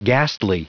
Prononciation du mot ghastly en anglais (fichier audio)
Prononciation du mot : ghastly